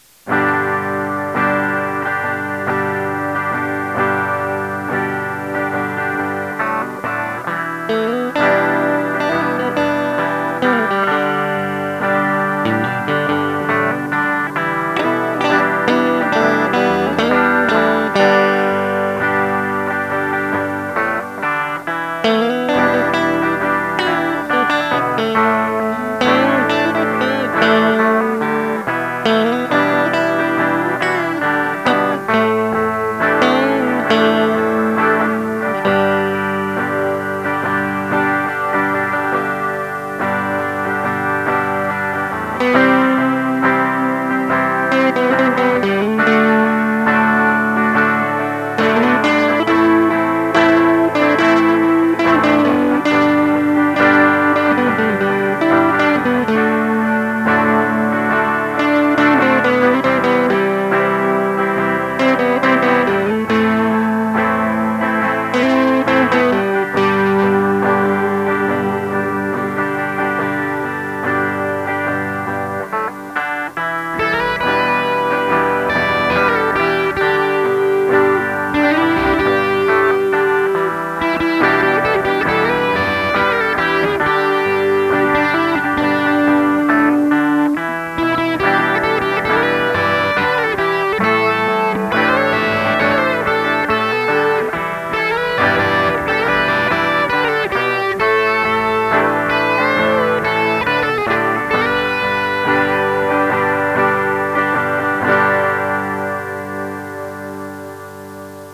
allez, je vous en met tout de même un petit, fait à l'arrache et avec un paing toutes les trois notes, histoire que vous ayez une idée du "bruit" qu'elle fait :wink:
J'ai oublié de préciser, la rhythmique est faite sur le manche 12 avec les deux micros en combinaison, le premier solo est sur le manche 6, avec la combinaison des micros manche et centre, le second avec le micro manche et un peu de crunch sur le pod (1ere génération) qui me sert de préamp, et le dernier solo avec le micro chevalet et plus de drive.